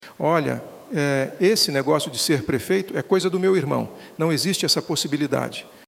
Feitos por Inteligência Artificial, os áudios imitam trejeitos da fala do político e afirmam um descontentamento com a possível candidatura dele para prefeito municipal nas Eleições 2024.
Em um dos áudios, a imitação da voz do ex-líder do Executivo maringaense alega que “esse negócio de ser prefeito é coisa do meu irmão”, tratando-se do atual secretário da Indústria, Comércio e Serviços do Paraná, Ricardo Barros.